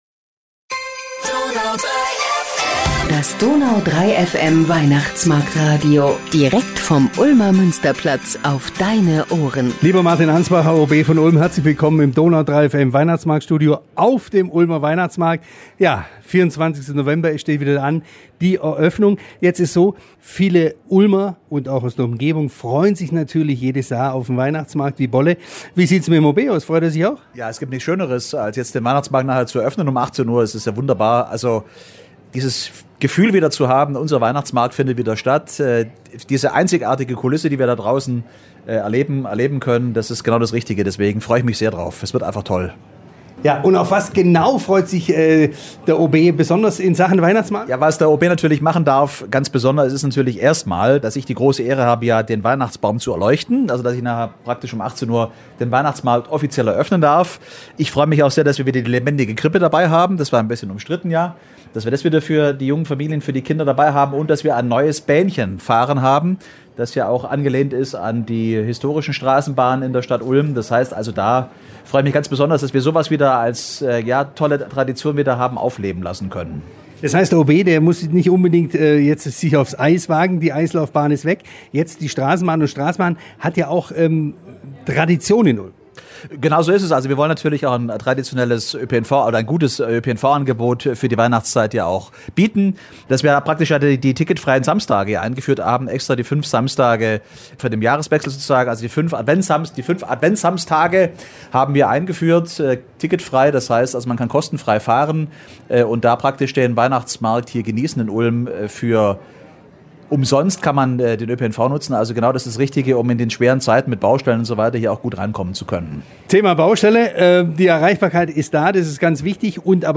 Das DONAU 3 FM Weihnachtsmarkt- Radio mit Oberbürgermeister Martin Ansbacher ~ Ulmer Weihnachtsmarkt-Podcast Podcast